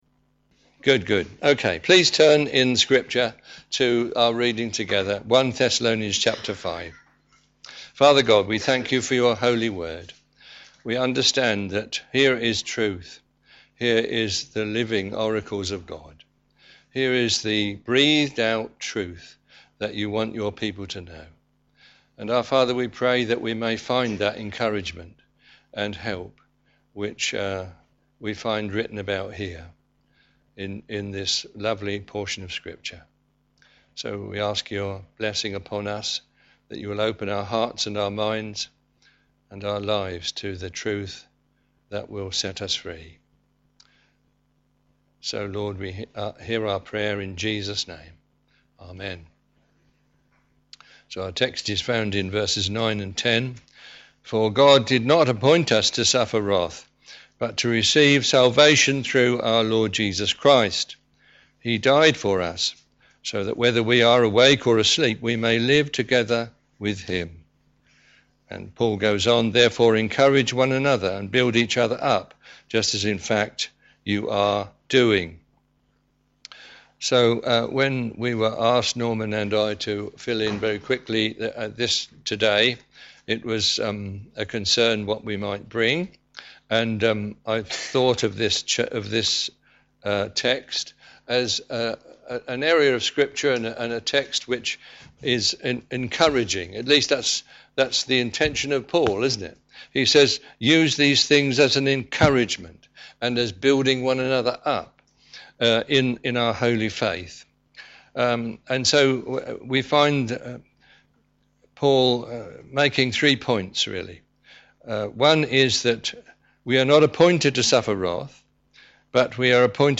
Media for a.m. Service on Sun 07th Jan 2018 10:30
Theme: Sermon